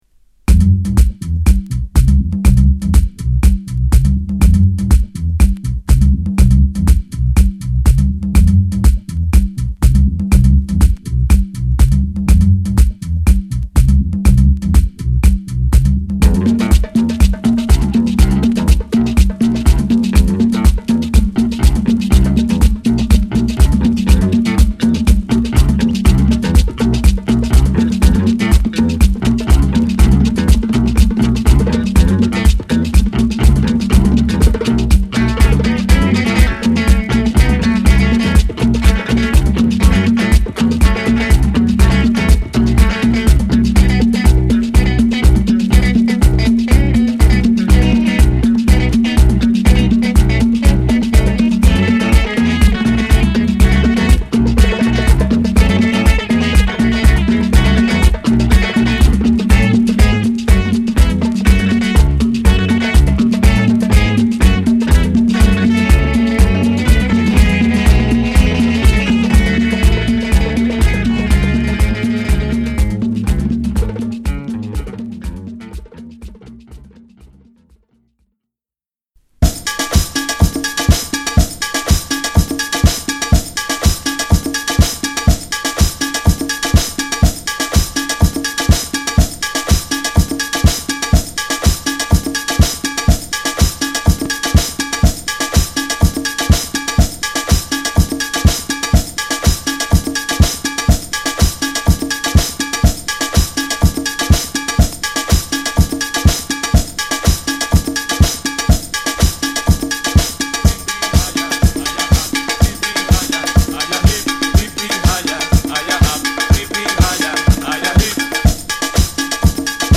パーカッシブでアフロビートにも通づる土着的なサウンドを、さらに使い易くリエディットされた4曲入りEP！